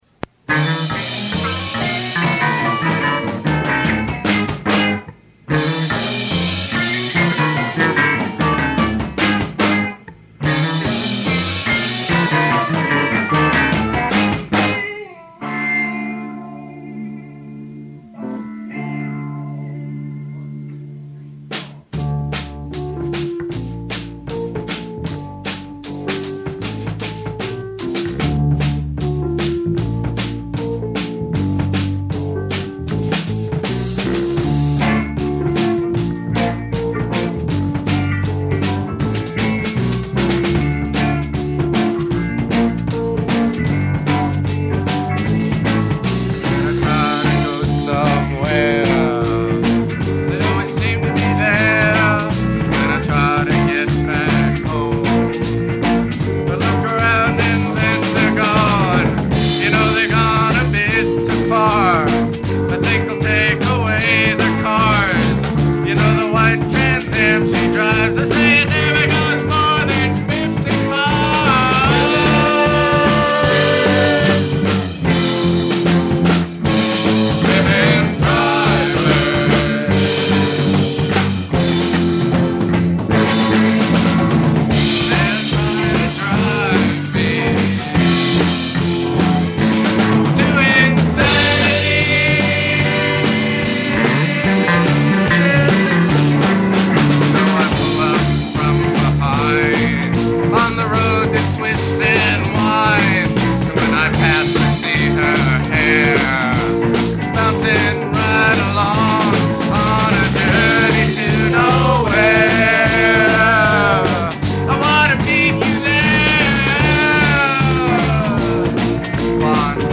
percussion, vocals
bass guitar
keyboards, vocals
guitar, vocals